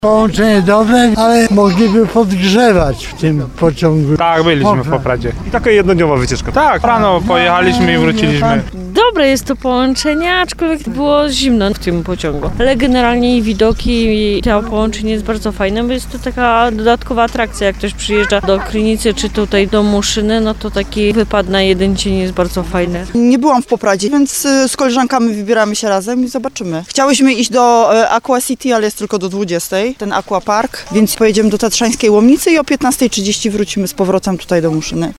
Pociąg Muszyna-Poprad jeszcze kursuje. Pytaliśmy pasażerów o wrażenia [ZDJĘCIA]